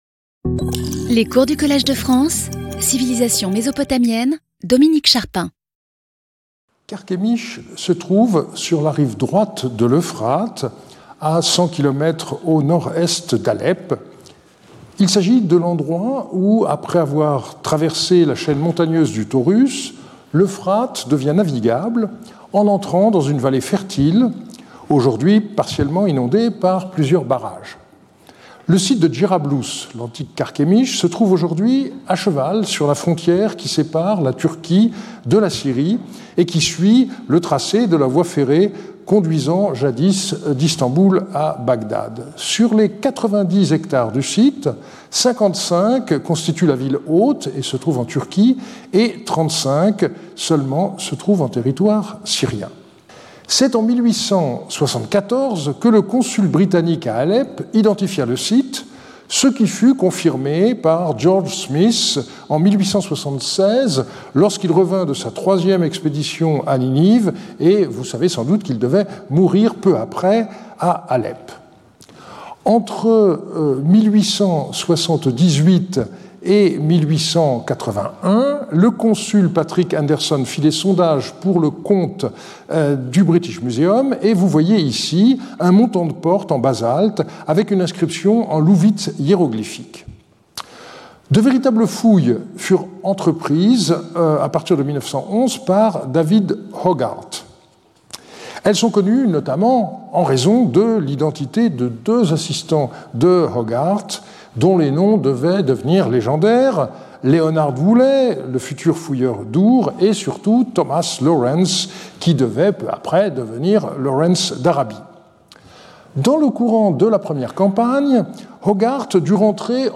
Sauter le player vidéo Youtube Écouter l'audio Télécharger l'audio Lecture audio Intervenant(s) Dominique Charpin Professeur du Collège de France Événements Précédent Cours 08 Déc 2025 11:00 à 12:00 Dominique Charpin Introduction.